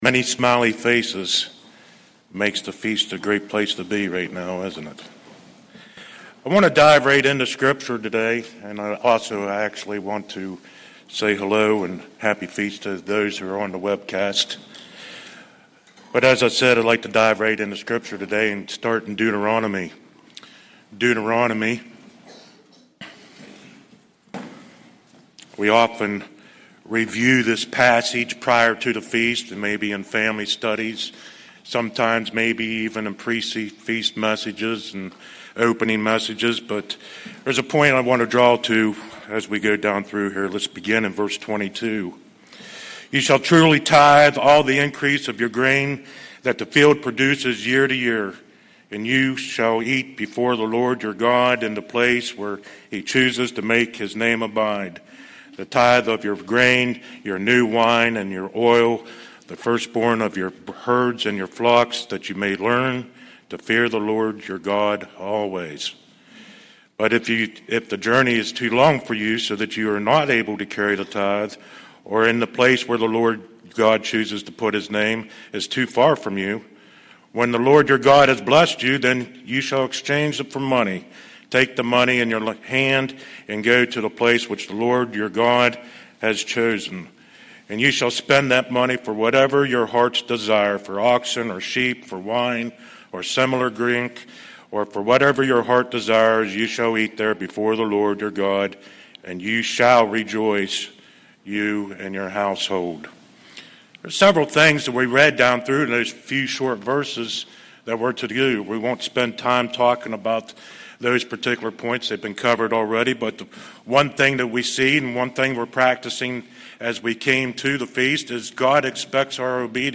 Sermons
Given in Morehead City, North Carolina